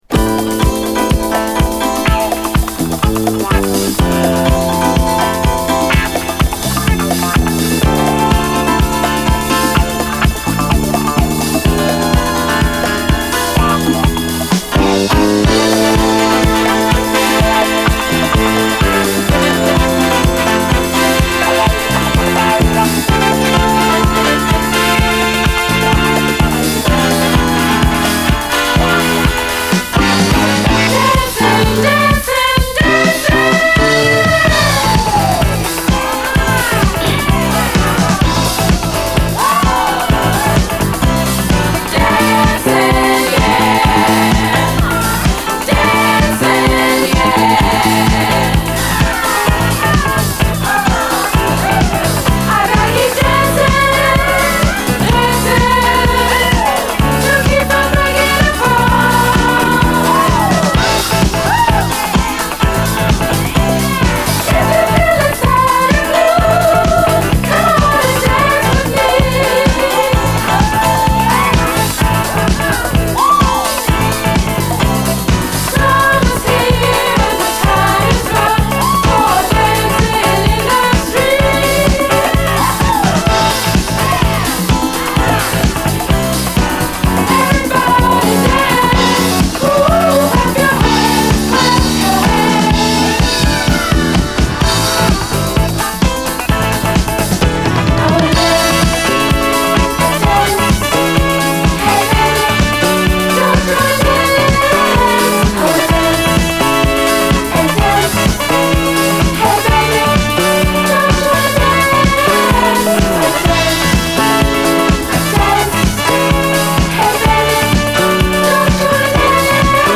SOUL, 70's～ SOUL, DISCO
派手でキャッチーなユーロ・ディスコ〜モダン・ソウル！